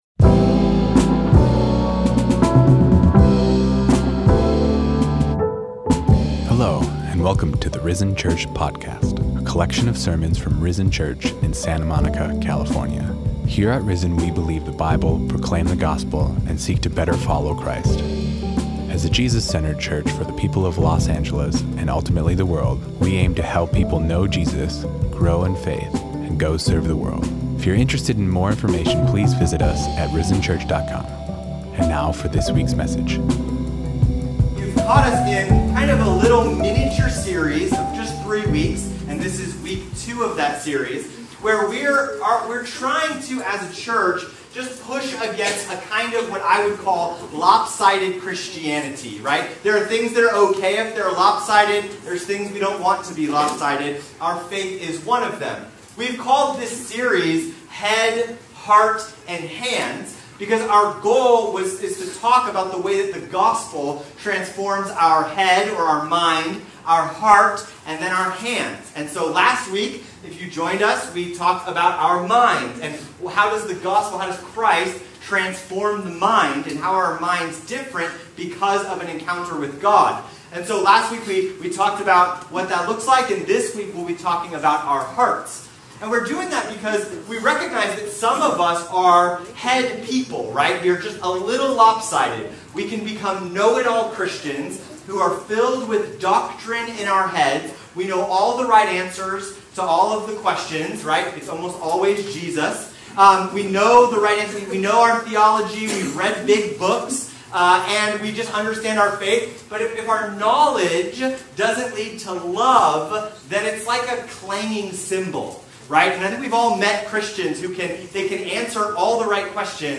Sermons | RISEN CHURCH SANTA MONICA, INC